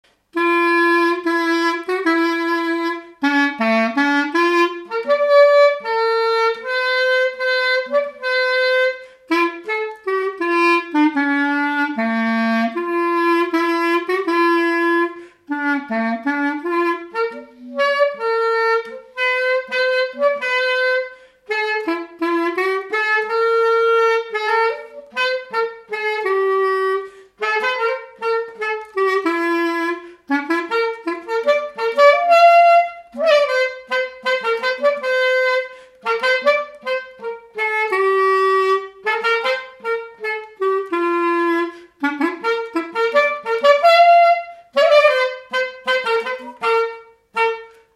Résumé instrumental
circonstance : fiançaille, noce
Pièce musicale inédite